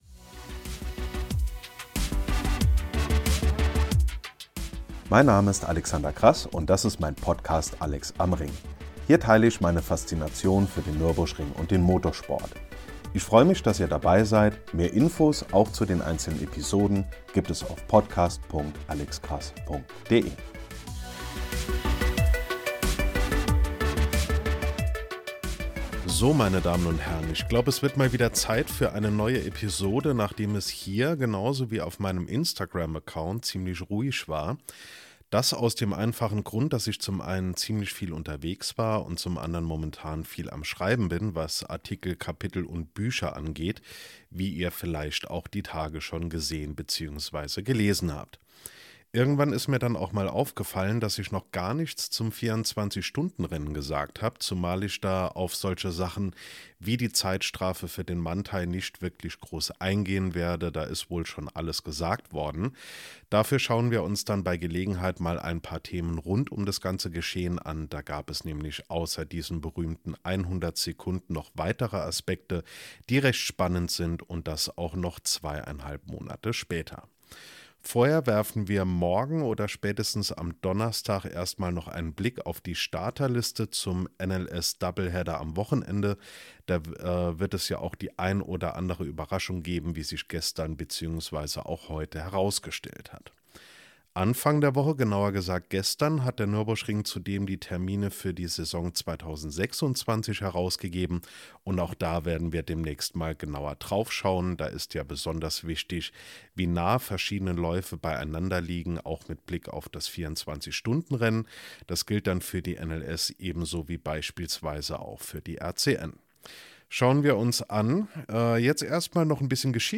In den Monaten Juni, Juli und August geschah einiges im Landkreis Adenau - dazu lese ich den dritten Teil meiner Kolumne zur Entstehung des Nürburgrings vor.